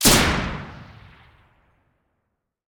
generalgun.ogg